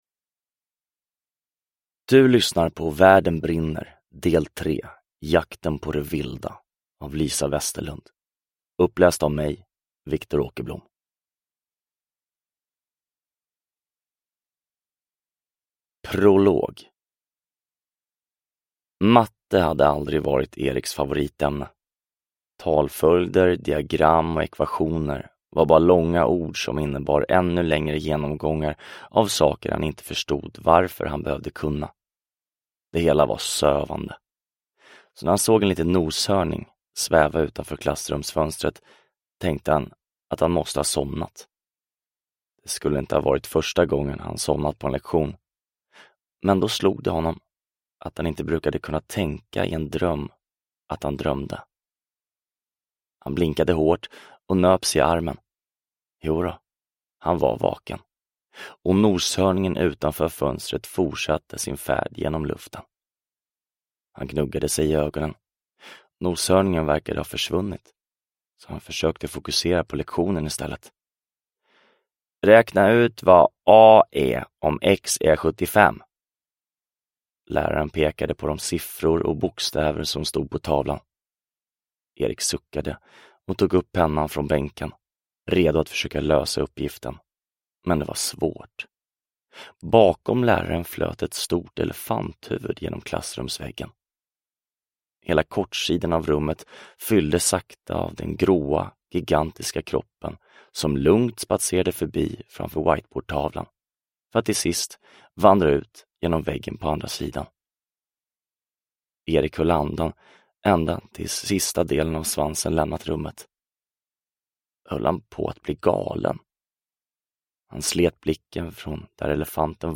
Jakten på det vilda – Ljudbok – Laddas ner